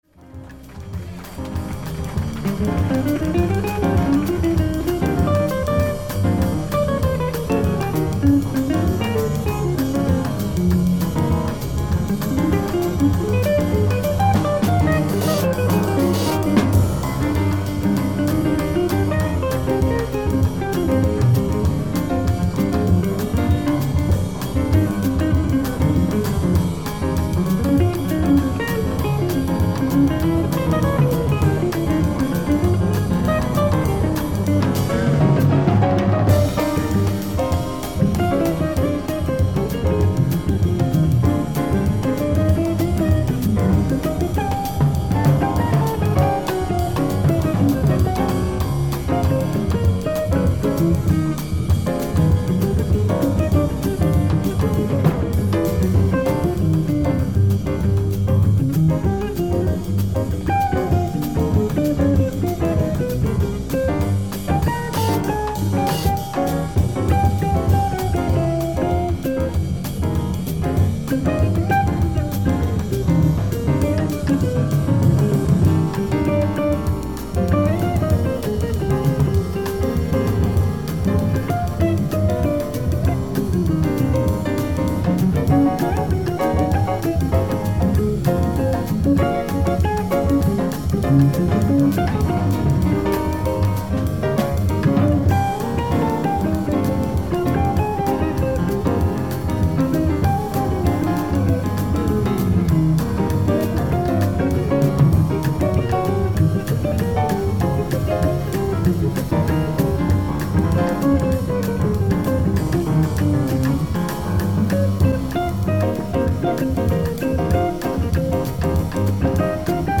ライブ・アット・カフェカリブ、プレインフィールド、ニュージャージー 04/29/1973
音質もサンプルをお聴き頂ければお判りの通り問題の無いサウンドボード音源です。
※試聴用に実際より音質を落としています。